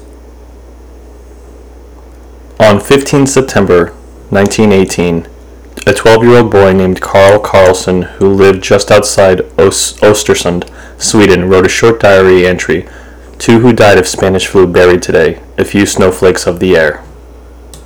Here are two readings.
Gain is at 3 o’clock.